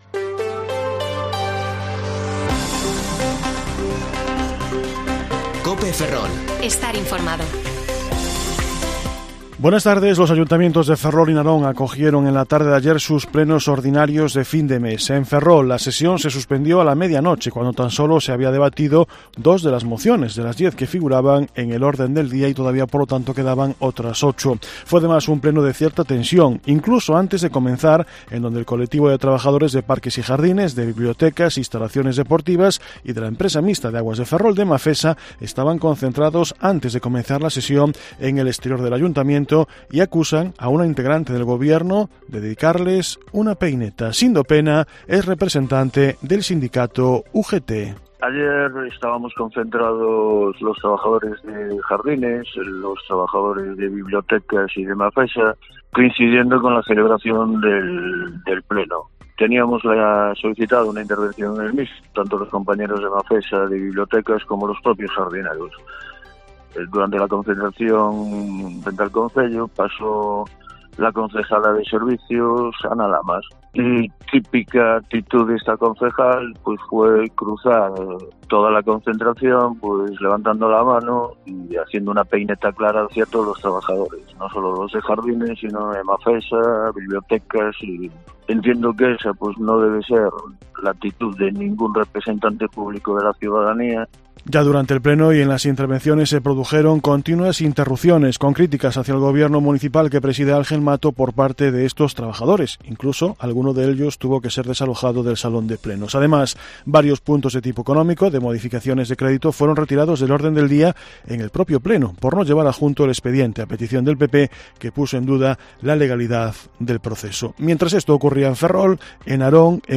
Informativo Mediodía COPE Ferrol 29/4/2022 (De 14,20 a 14,30 horas)